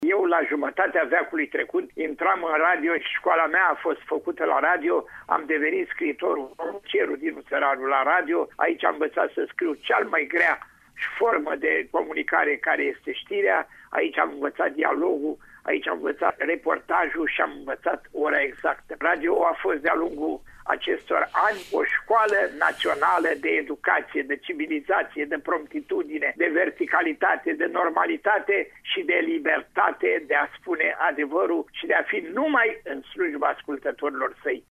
La rândul său, scriitorul, directorul de teatru şi omul de radio, Dinu Săraru, a vorbit despre ce a însemnat, pentru el, această instituţie: